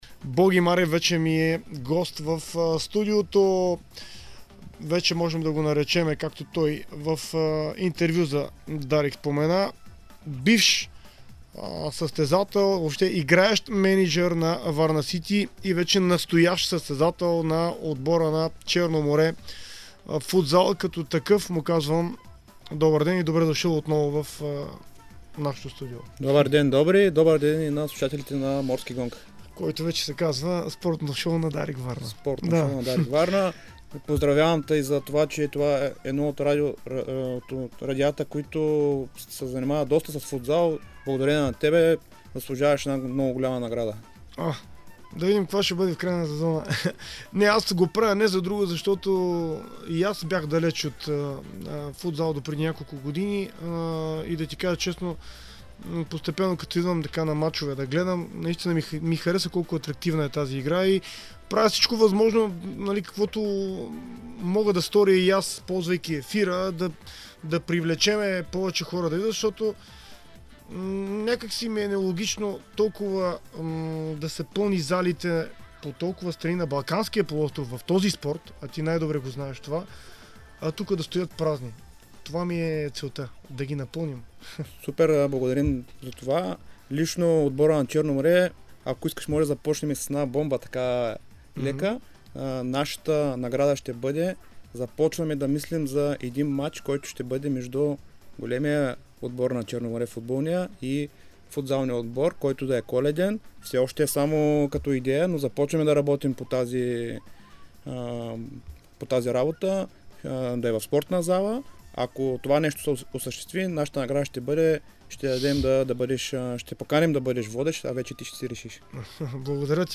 споделиха в интервю за Дарик радио очакванията си за представянето на техните отбори в предстоящата Национална лига по футзал. Първенството на България ще стартира в началото на другия месец.